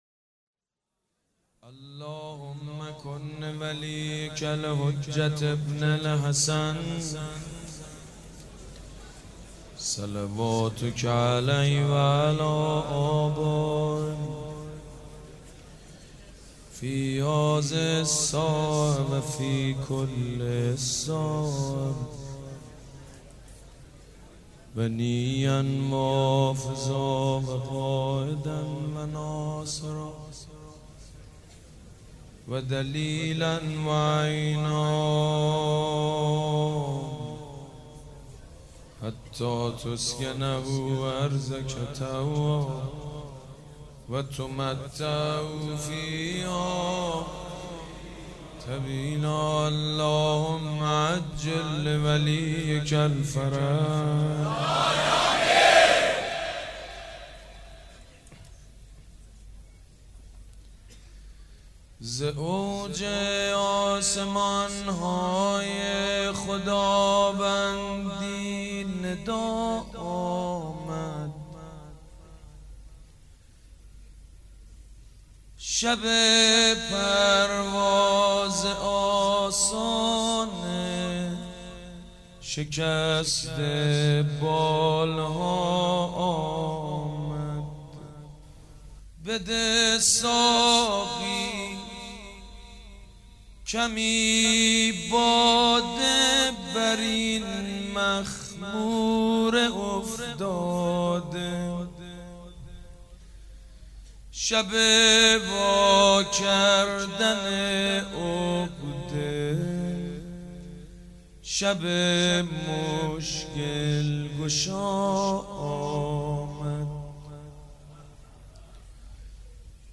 حاج سید مجید بنی فاطمه سه شنبه 11 اردیبهشت 1397 هیئت ریحانه الحسین سلام الله علیها
سبک اثــر شعر خوانی
شعر خوانی.mp3